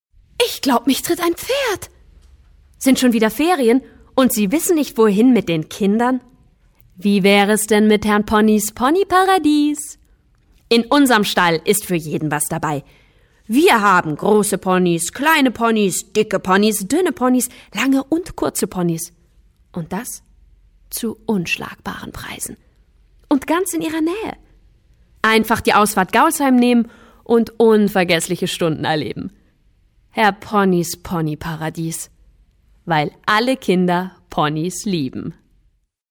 Werbung und Imageclip Demo